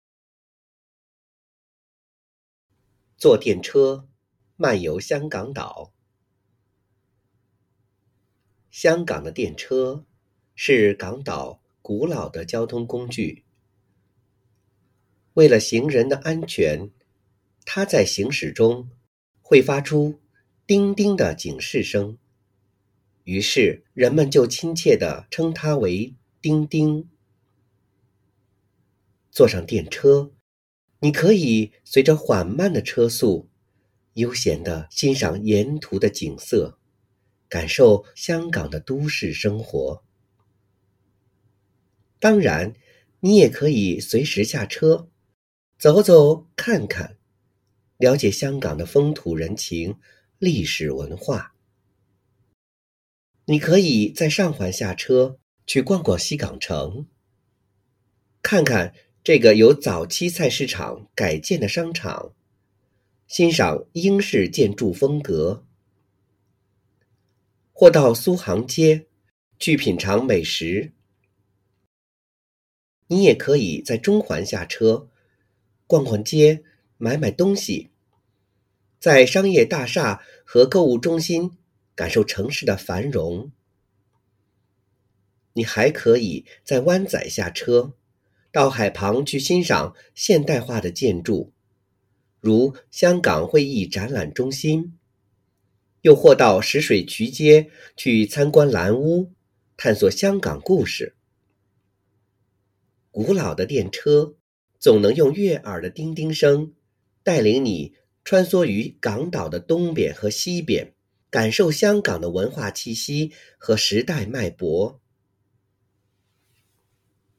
編號篇名篇章及學與教建議朗讀示範篇章附拼音